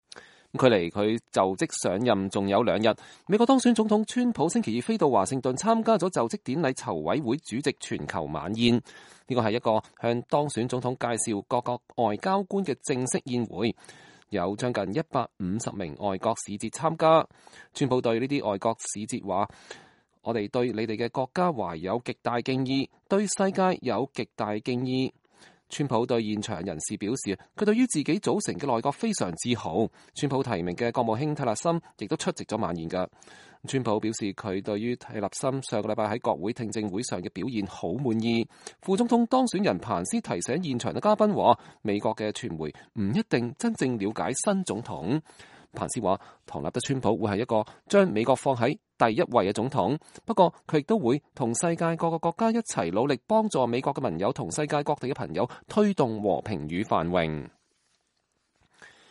川普在星期二的晚宴上講話